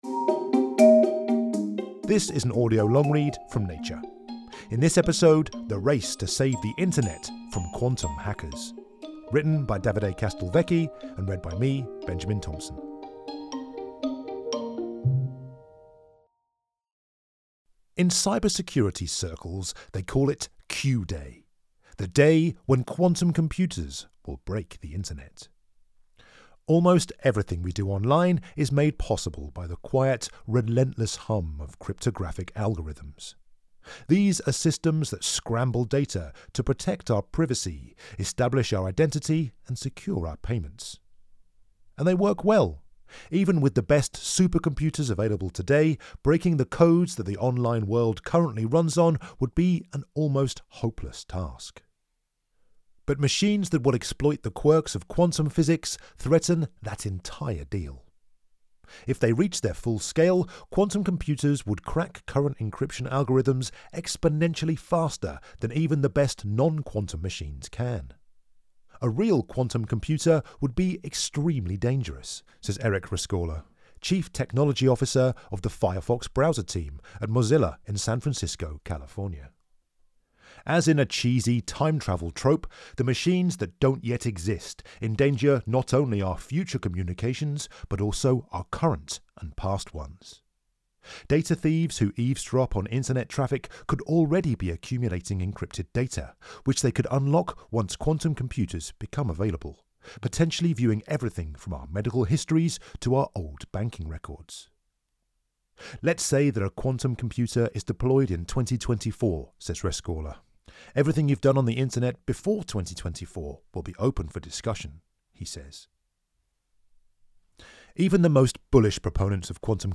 NATURE PODCAST 28 February 2022 Audio long-read: The race to save the Internet from quantum hackers Researchers are scrambling to develop encryption systems that could defeat future quantum computers.